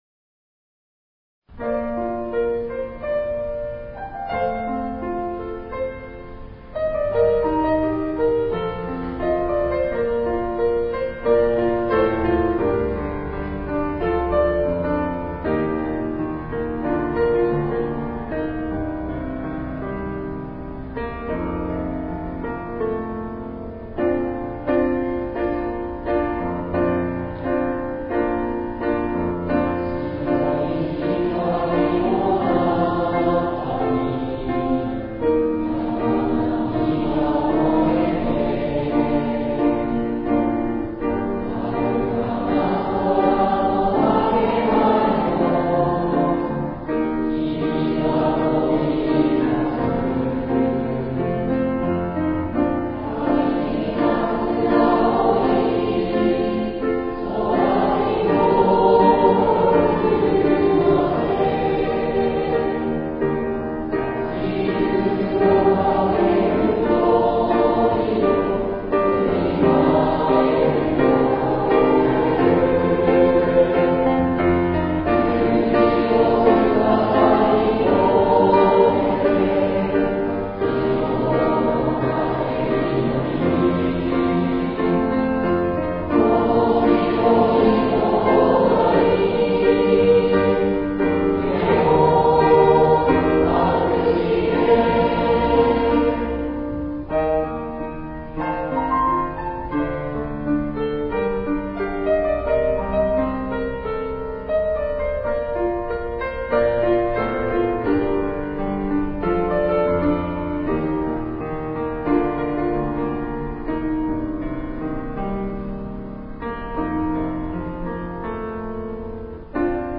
生徒たちのうたごえ♪〜最後の学級活動〜
昨日の２年生の学級活動での最後の学活の様子です。
※ クリックしていただくと生徒たちの歌声をお聴きいただけます。